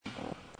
I have done a lot of recordings with my MP3 player off the FM and in each one have a buzz noise ever few seconds in the recording.
I have a included a sample of the buzz noise that I hear in the recordings.
buzz noise.mp3